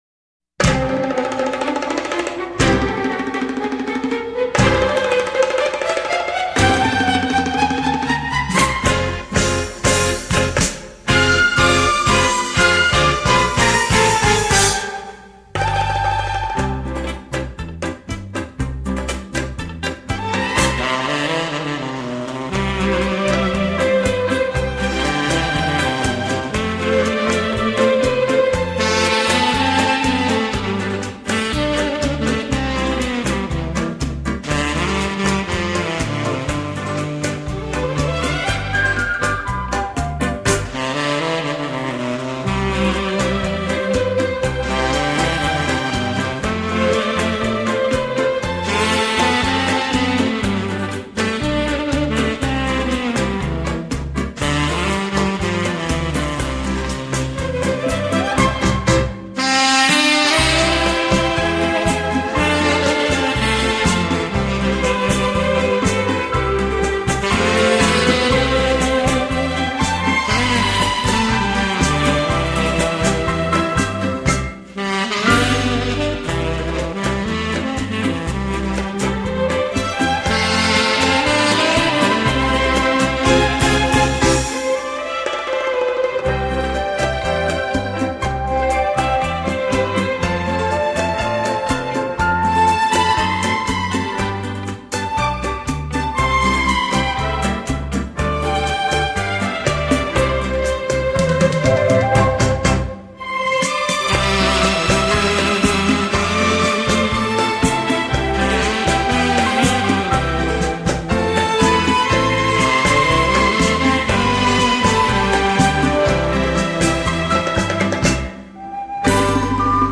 音乐风格:J-POP
最佳舒壓的休閒音樂， 精選各種樂器之經典暢銷曲。
薩克斯風